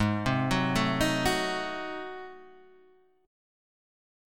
G#7b9 chord {4 3 4 2 4 2} chord